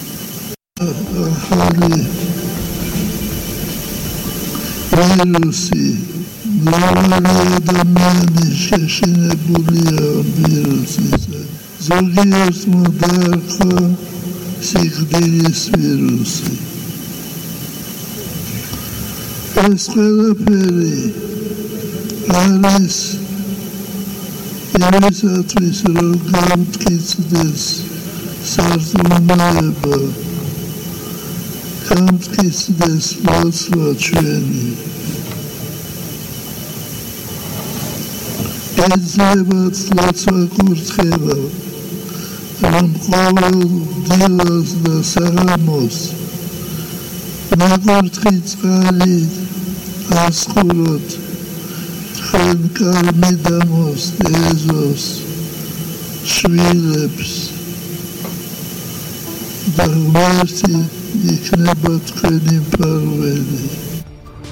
სრულიად საქართველოს კათოლიკოს-პატრიარქი ილია მეორე კორონავირუსზე საუბრობს და მრევლს კურთხევას აძლევს, კარ-მიდამოსა და შვილებს დილა-საღამოს ნაკურთხი წყალი ასხურონ.